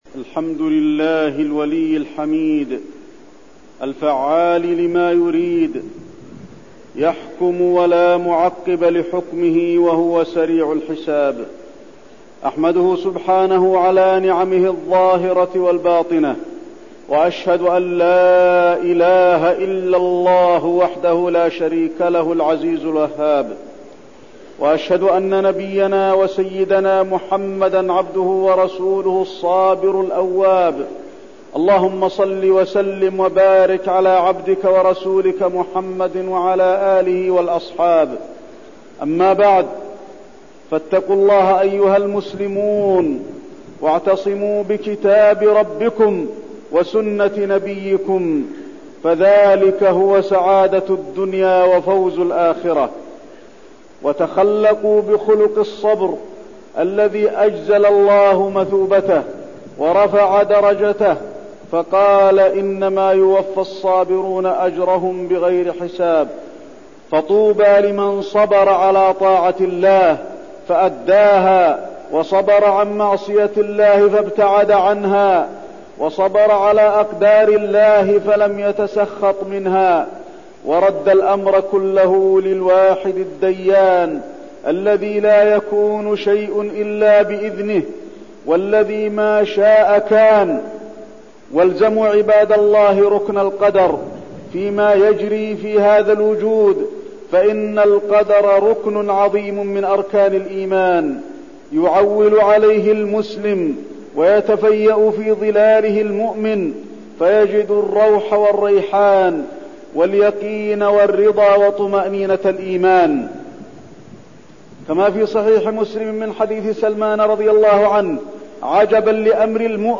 تاريخ النشر ١٤ ذو الحجة ١٤١٠ هـ المكان: المسجد النبوي الشيخ: فضيلة الشيخ د. علي بن عبدالرحمن الحذيفي فضيلة الشيخ د. علي بن عبدالرحمن الحذيفي الإيمان بالقدر وحادثة منى